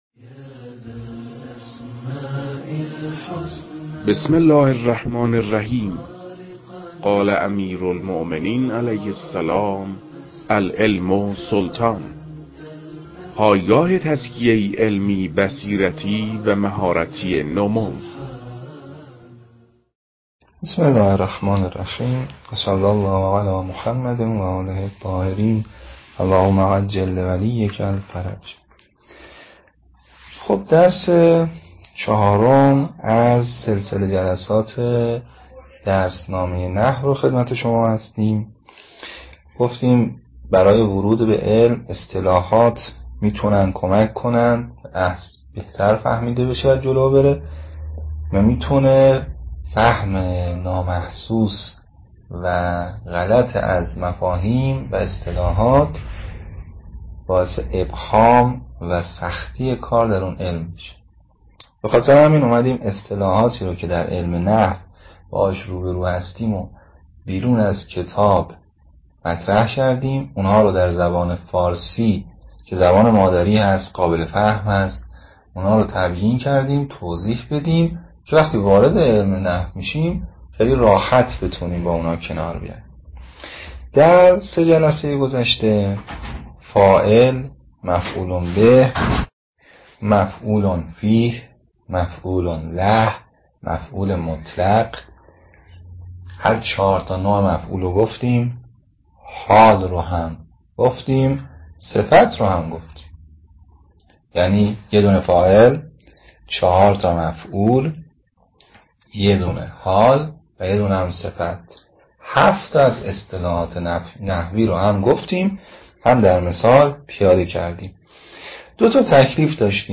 در این بخش، کتاب «درسنامه نحو» که اولین کتاب در مرحلۀ آشنایی با علم نحو است، به ترتیب مباحث کتاب، تدریس می‌شود. صوت‌های تدریس